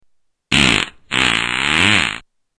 Звуки пердежа
1. Пердеж человека